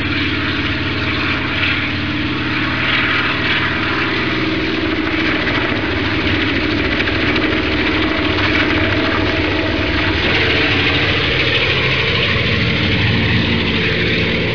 دانلود آهنگ طیاره 59 از افکت صوتی حمل و نقل
دانلود صدای طیاره 59 از ساعد نیوز با لینک مستقیم و کیفیت بالا
جلوه های صوتی